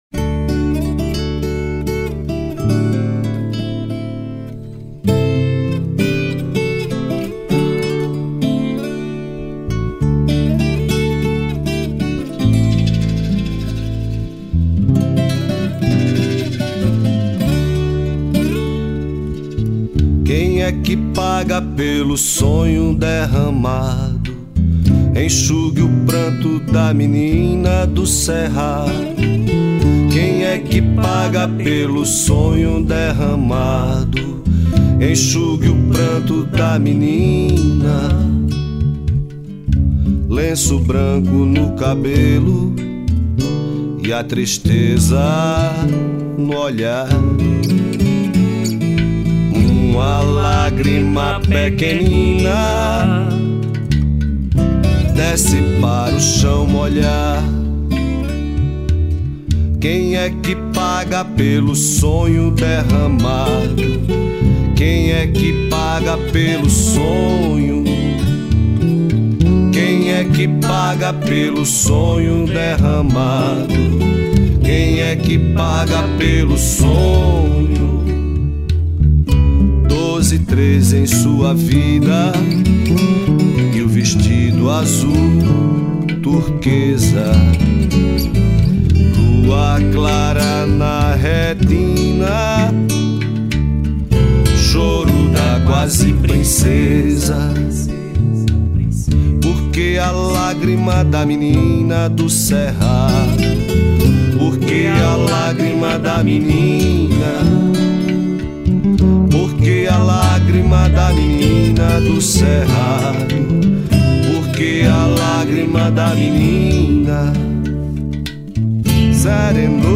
04:37:00   Ciranda